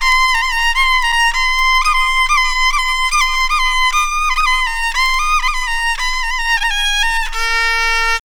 KOREAVIOLN-R.wav